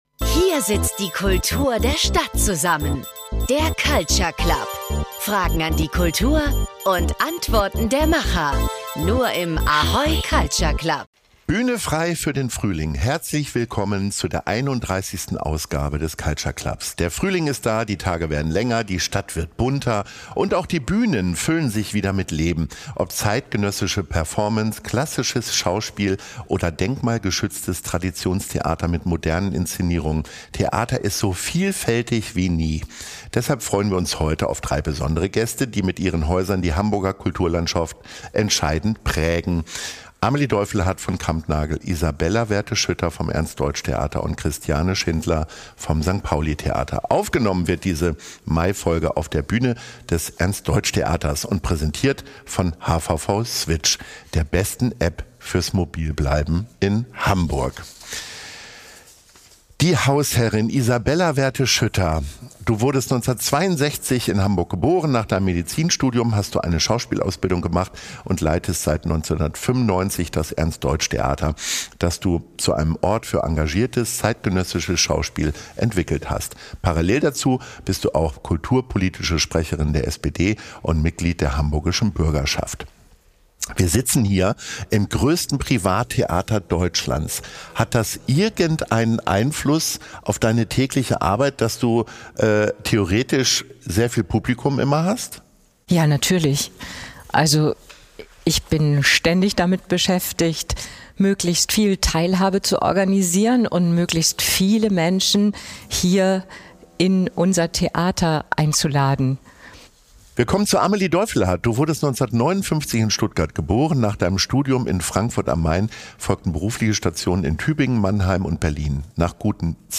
Es wird persönlich, politisch und leidenschaftlich – mit vielen Einblicken hinter die Kulissen des Hamburger Theaterfrühlings. Ein Gespräch über Vielfalt, Veränderung und Visionen – aufgenommen im Ernst-Deutsch-Theater und präsentiert von hvv switch, der besten App fürs mobil bleiben in Hamburg.